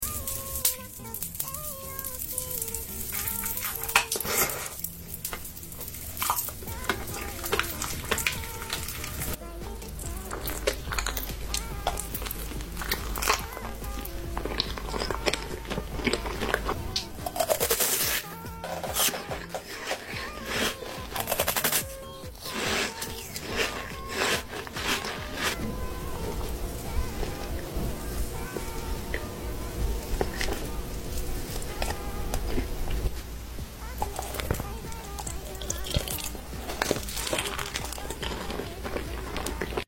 SLEEP SOUND asmr eating